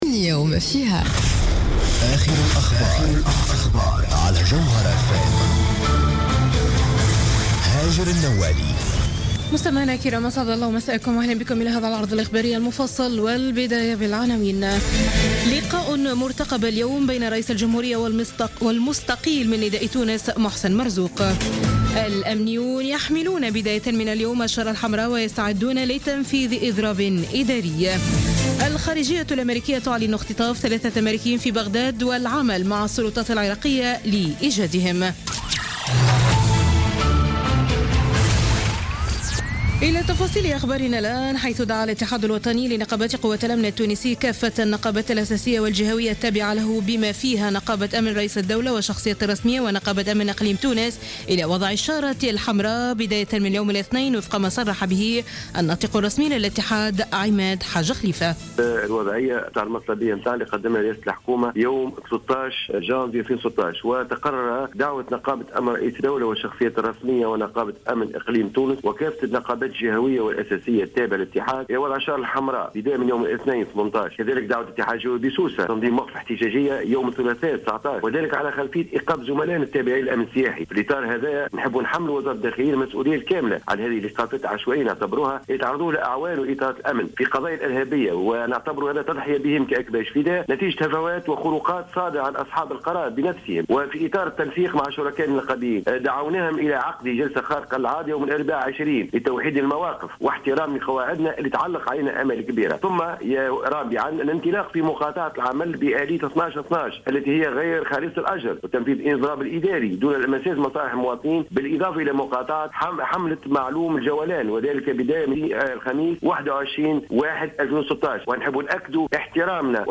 نشرة أخبار منتصف الليل ليوم الإثنين 18 جانفي 2016